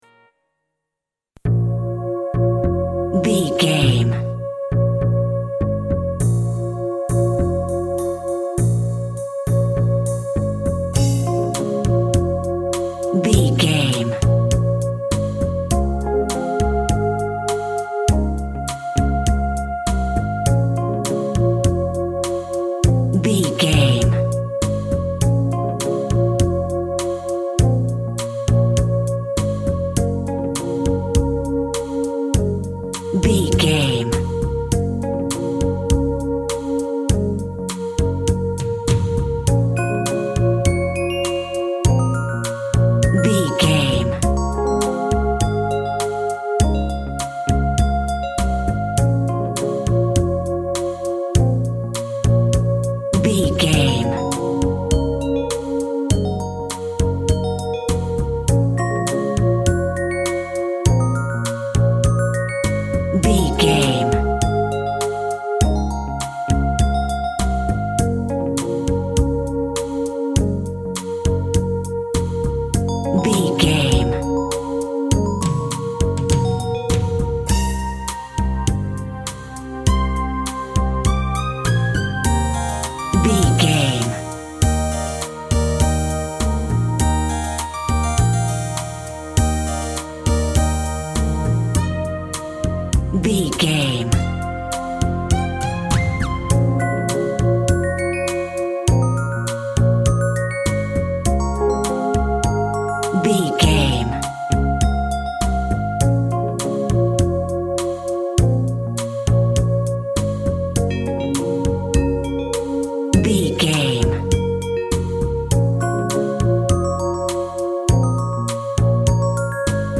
Aeolian/Minor
C#
Slow
uplifting
hypnotic
dreamy
tranquil
smooth
drums
synthesiser
electric piano
pop
electronic
synth bass
synth lead